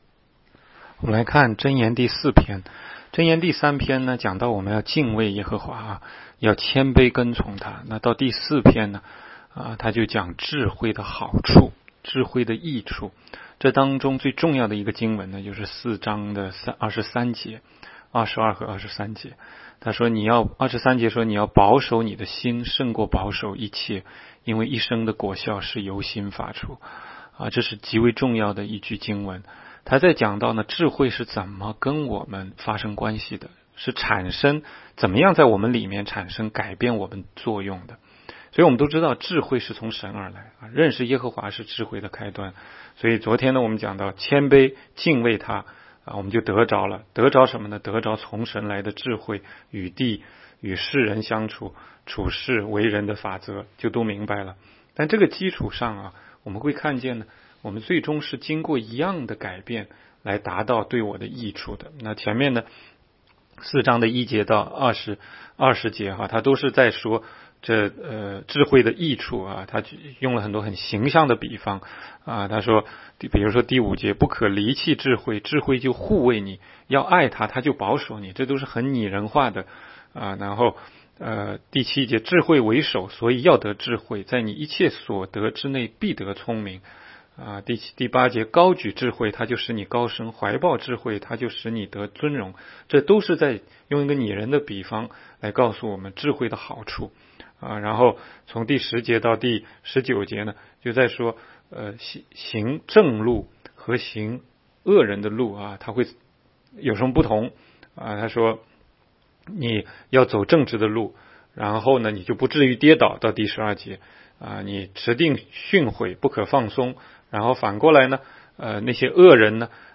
16街讲道录音 - 每日读经 -《 箴言》4章